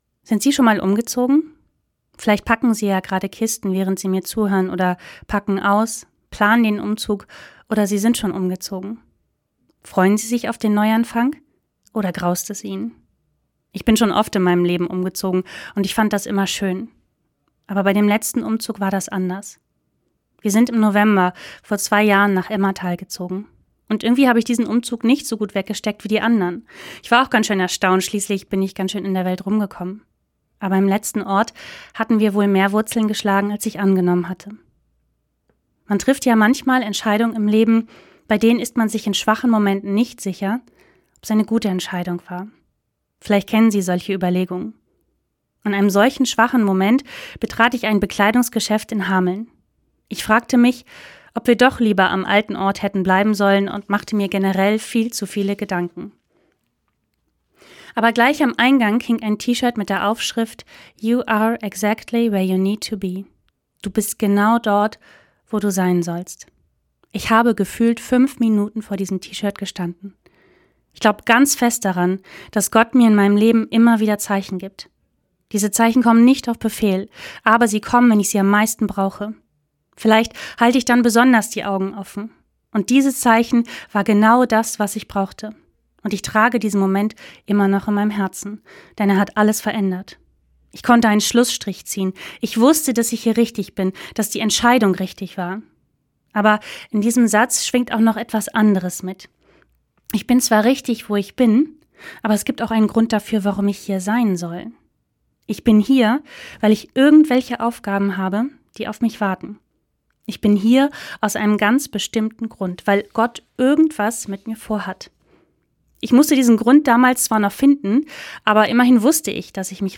Radioandacht vom 13. März – radio aktiv